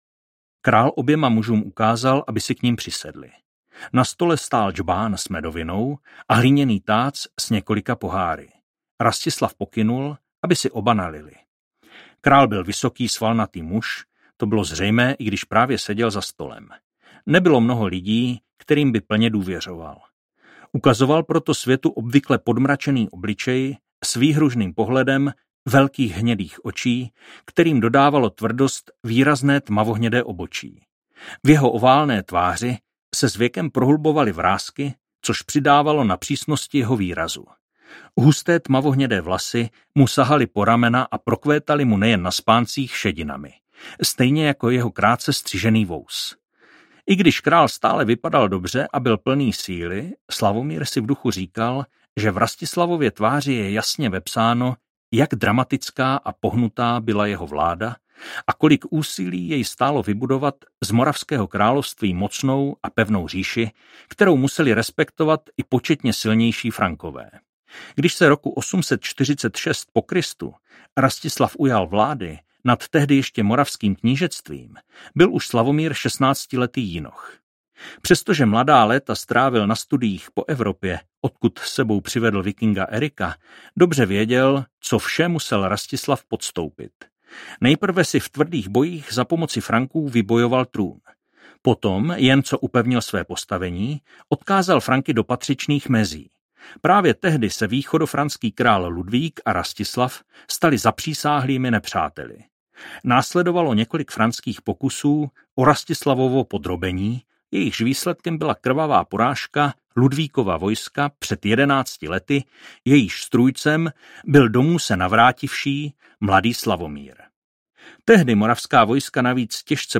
Případ nitravské mince audiokniha
Ukázka z knihy